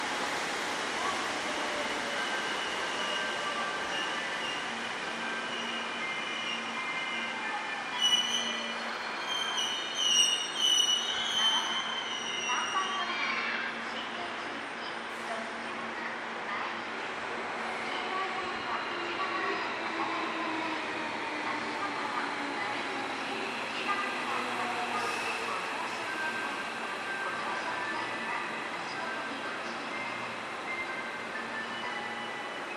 この駅では接近放送が設置されています。
接近放送特急　新開地行き接近放送です。